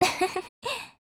贡献 ） 协议：Copyright，其他分类： 分类:SCAR-H 、 分类:语音 您不可以覆盖此文件。